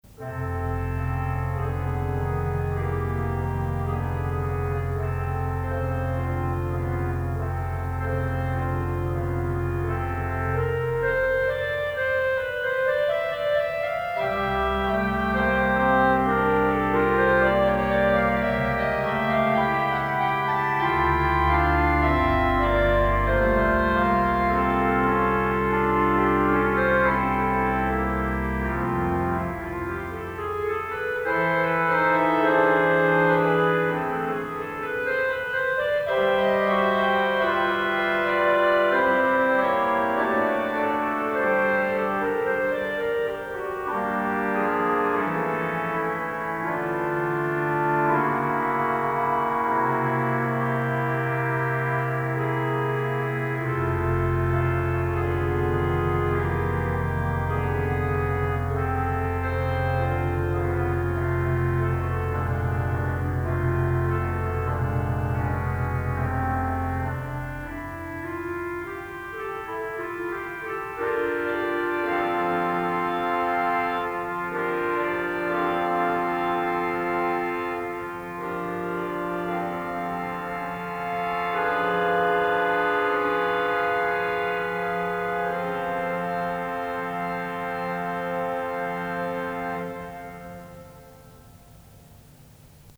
Yksityinen nauhoite.